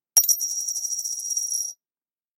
На этой странице собраны звуки копилки: от звонкого падения монет до глухого стука накопленных сбережений.